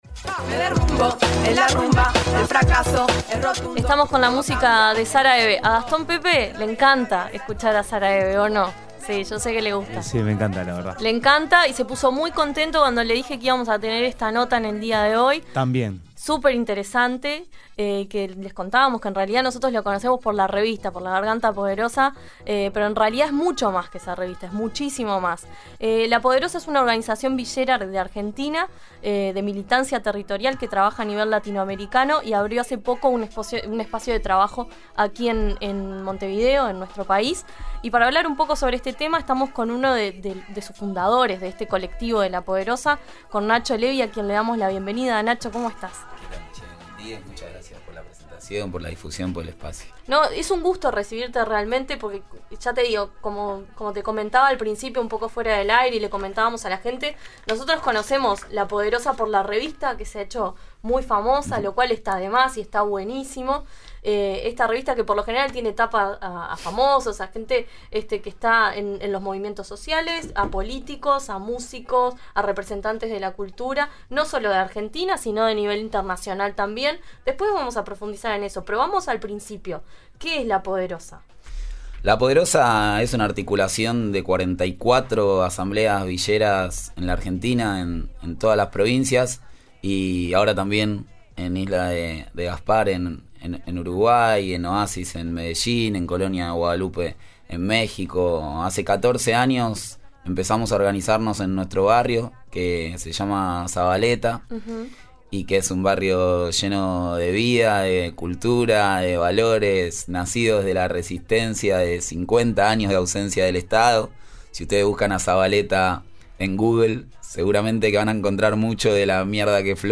Dialogamos sobre el trabajo de La Poderosa y las diferentes herramientas de visibilización que han desarrollado, entre ellas la revista La Garganta Poderosa Resistencia Villera, que se ha hecho conocer por sus tapas que contienen la imagen de algún actor político, cultural o social gritando.